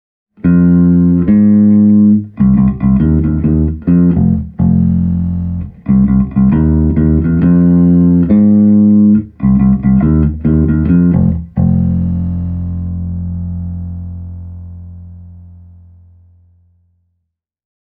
Isobaarinen Smart Power -kaappi kuulostaa ilahduttavan isolta ja lihaksikkaalta, ja myös potkua löyttyy mielin määrin.
Äänitin seuraavat esimerkipätkät japanilaisella Squier Jazz -bassolla, sekä passivisella MM-humbuckerilla varustetulla P-Man-bassollani:
Jazz Bass – sormilla
jazz-bass-e28093-fingerstyle.mp3